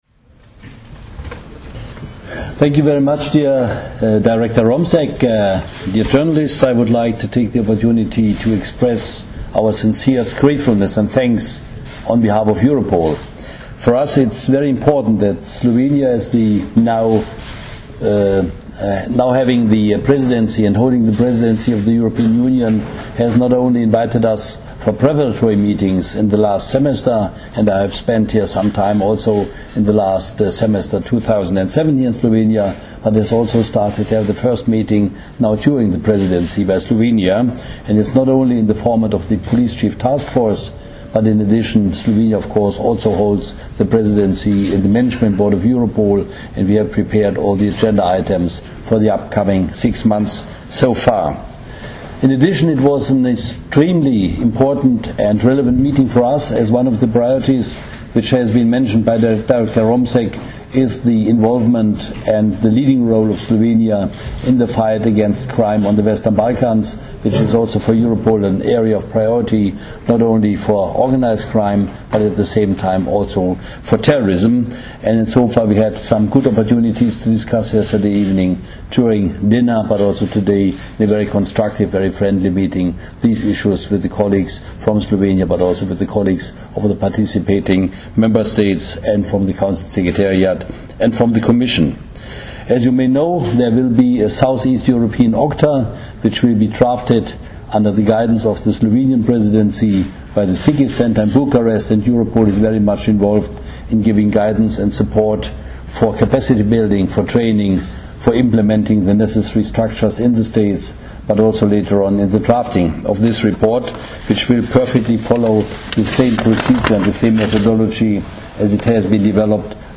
V okviru zasedanja sta generalni direktor slovenske policije Jože Romšek in direktor Europola Max-Peter Ratzel podala tudi izjavo za medije o srečanju in glavnih temah pogovorov.